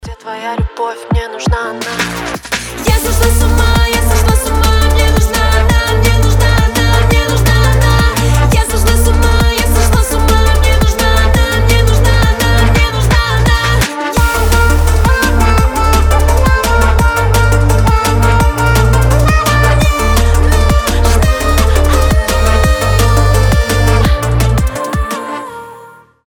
• Качество: 320, Stereo
громкие
женский голос
Cover
быстрые
драм энд бейс